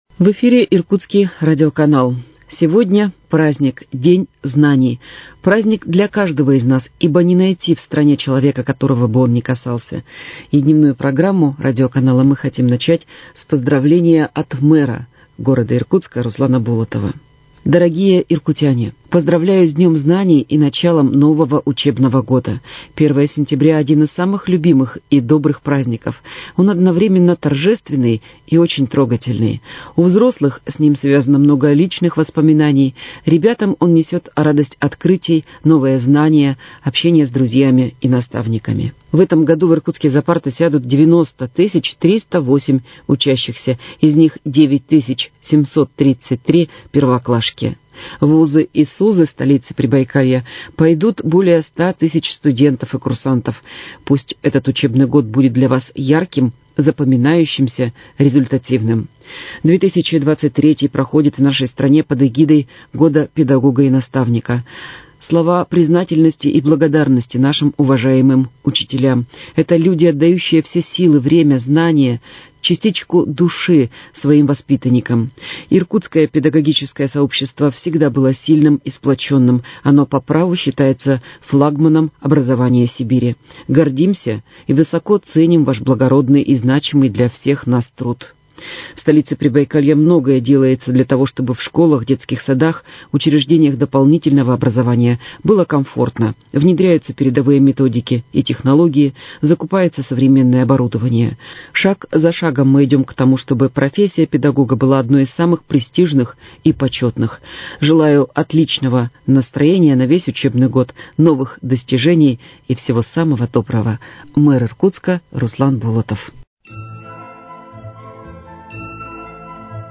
Поздравление с Днем знаний от мэра Иркутска Руслана Николаевича Болотова